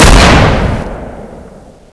deagle-1.wav